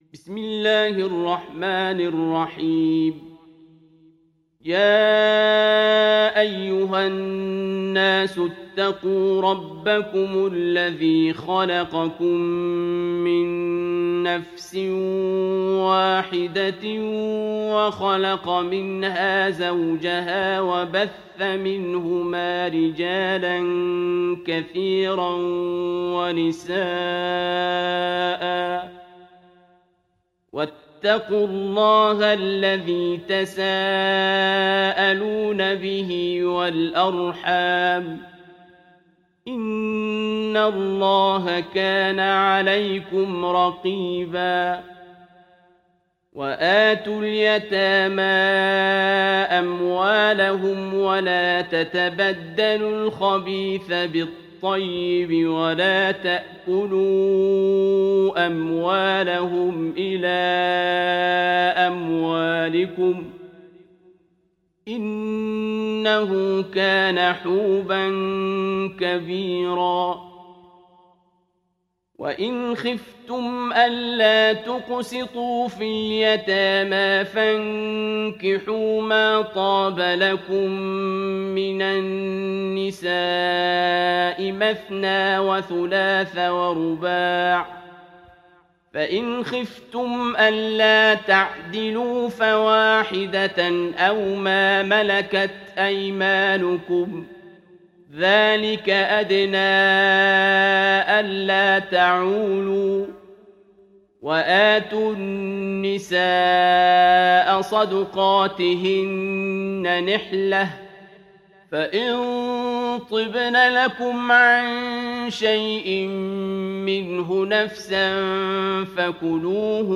دانلود سوره النساء mp3 عبد الباسط عبد الصمد (روایت حفص)